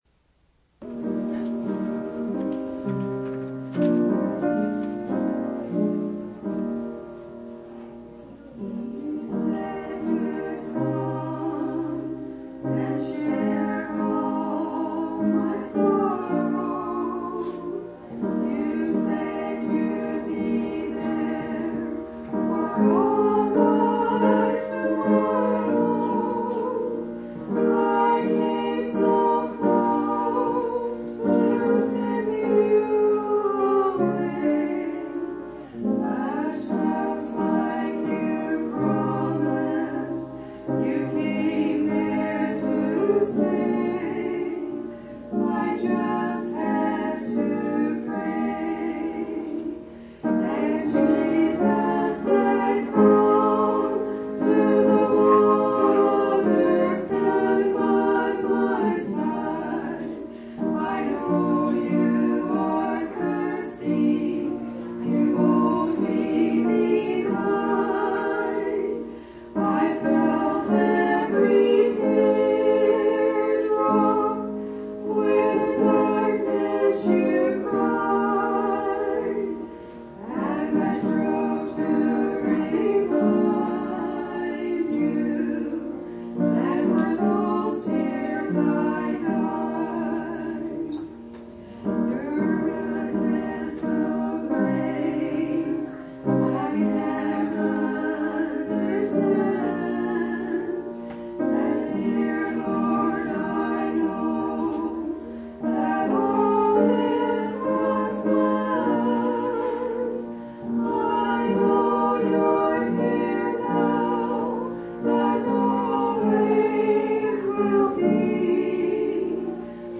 1/23/1983 Location: Phoenix Local Event